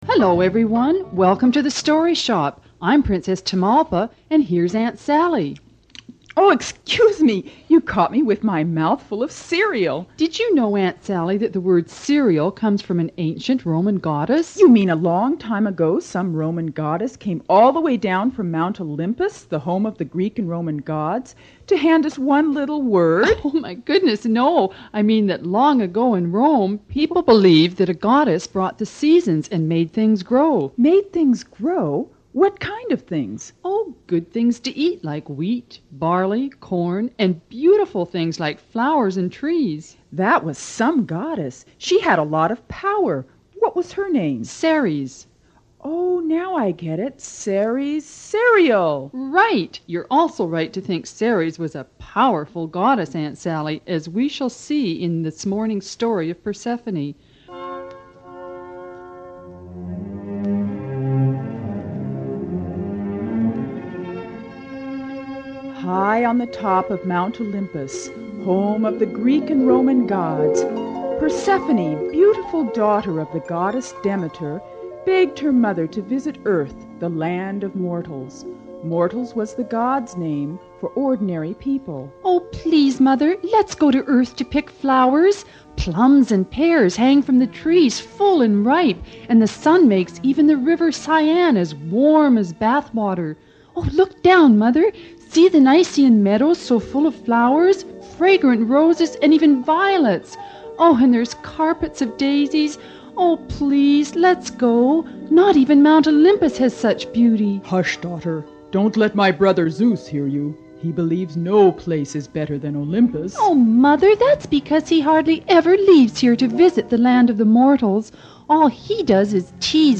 Demeter and Persephone Greek Myth - Children's Free Audio Book Story Download
Demeter-and-Persephone-Greek-Myth-Free-Audio-Story-for-Kids-0018.mp3